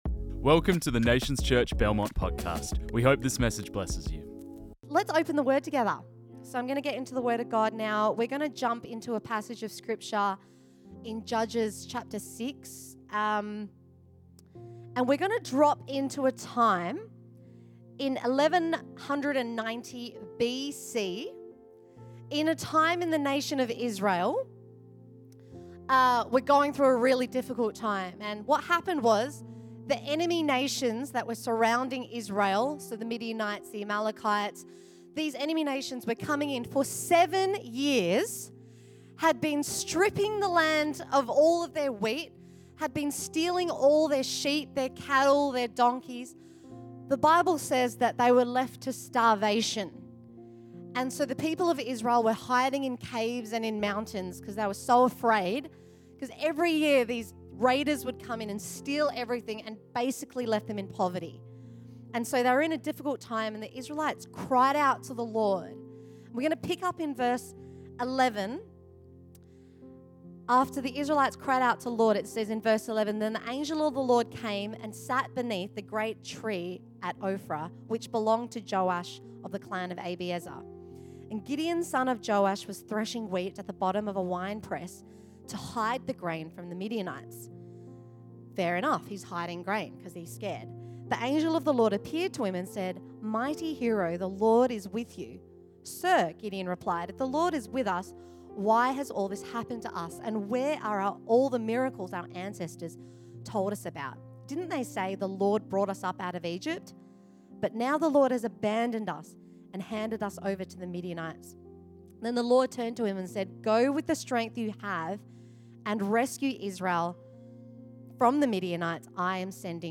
This message was preached on 10 March 2024.